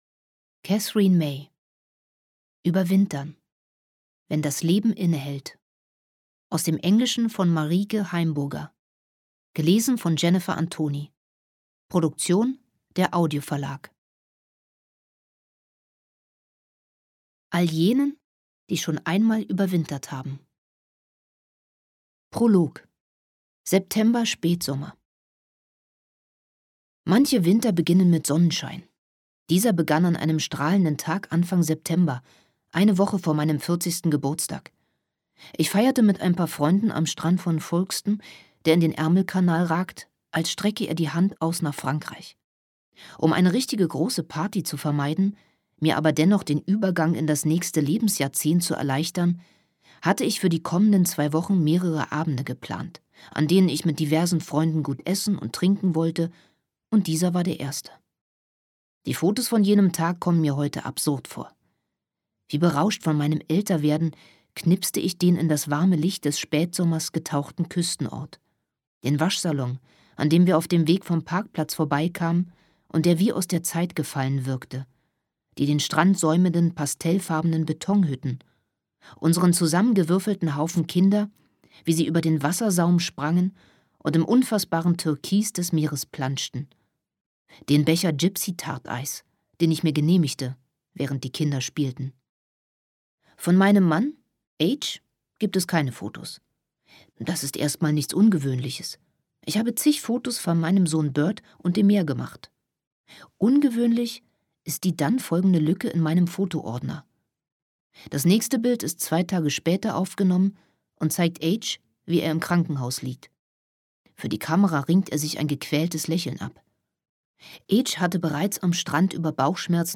Hörbuch: Überwintern.